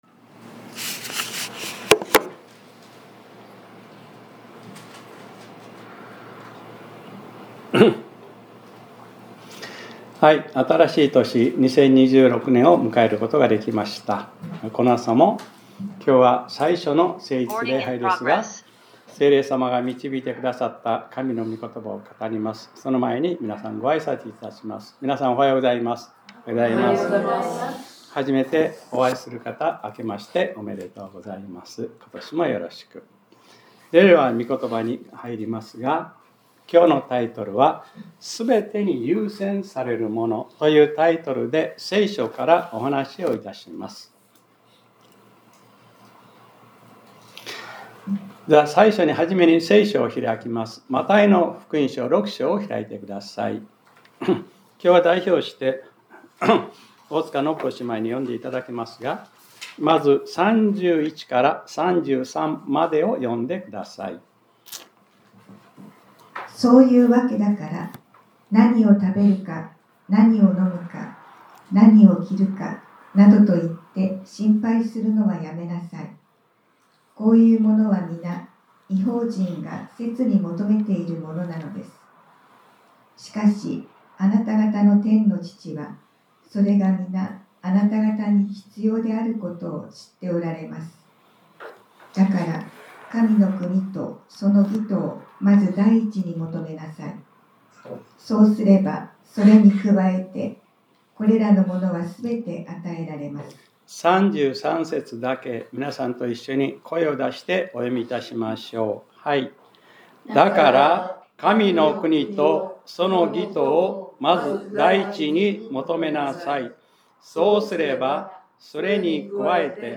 2026年01月04日（日）礼拝説教『 すべてに優先されるもの 』